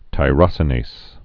(tī-rŏsə-nās, -nāz)